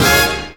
JAZZ STAB 6.wav